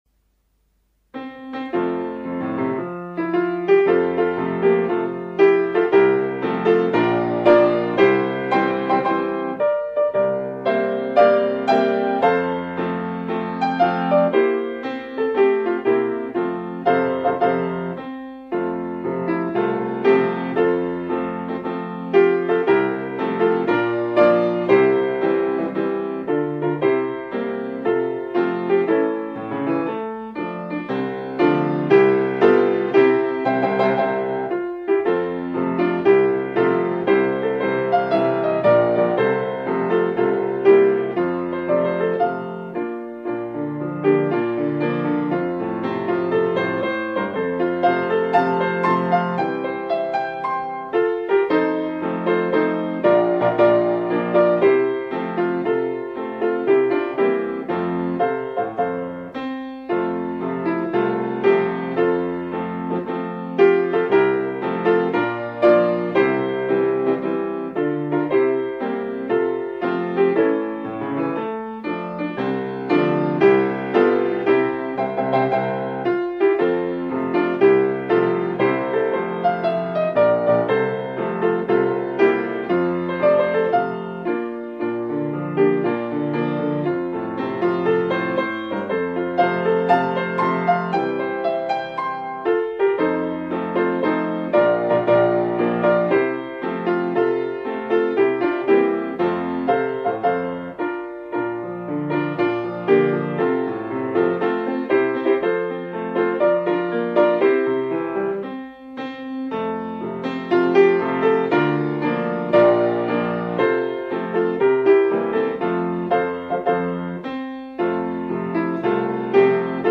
ピアノ伴奏版
新たな収録は平成25年3月22日くにたち市民芸術小ホールのスタジオで行いました。